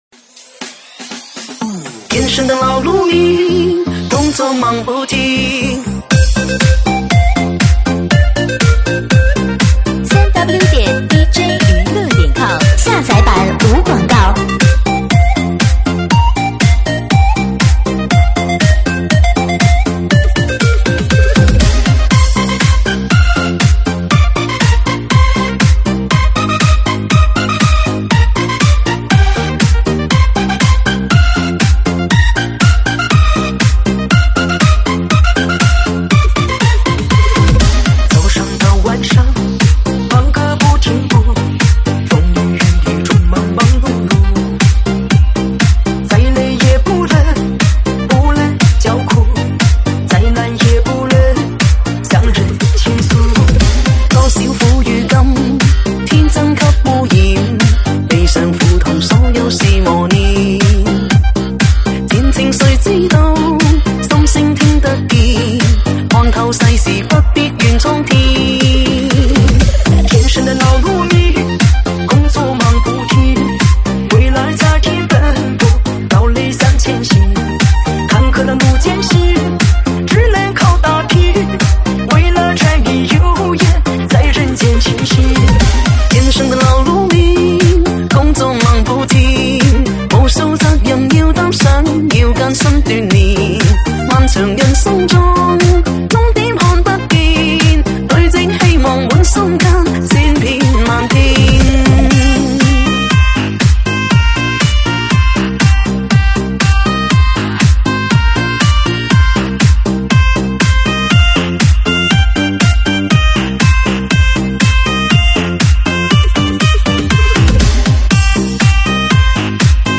舞曲类别：快四